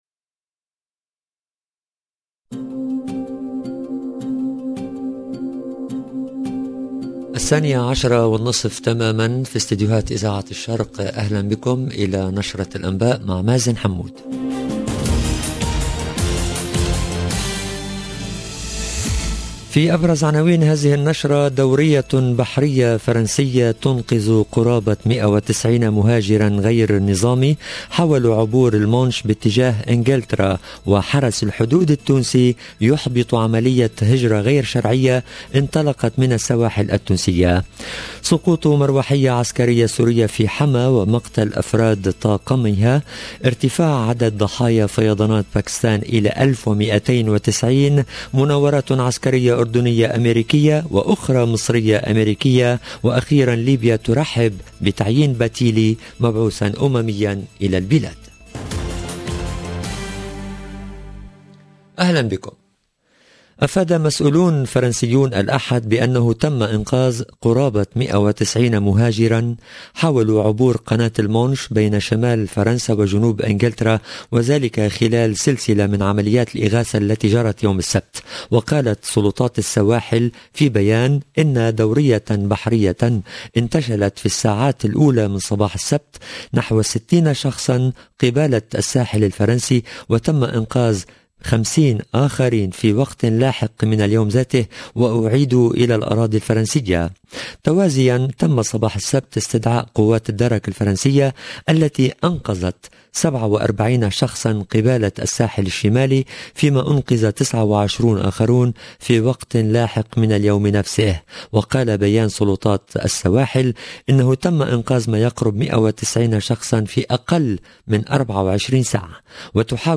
LB JOURNAL EN LANGUE ARABE دورية بحرية فرنسية تنقذ قرابة ١٩٠ مهاجراً غير نظامي حاولوا عبور المانش باتجاه انكلترا